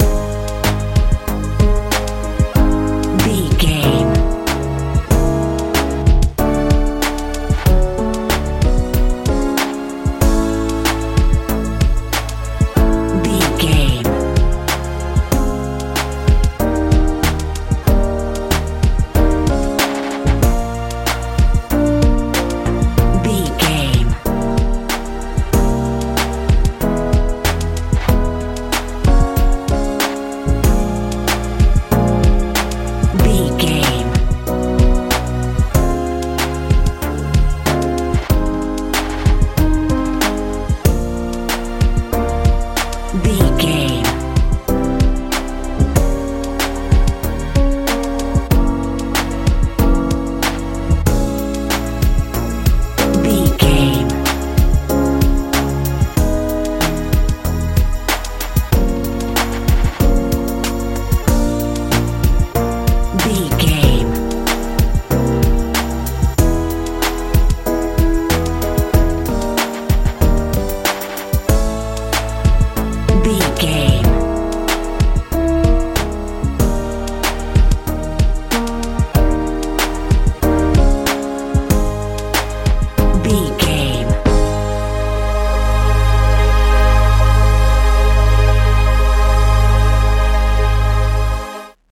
modern pop feel
Ionian/Major
A♭
bright
synthesiser
bass guitar
drums
80s
light
relaxed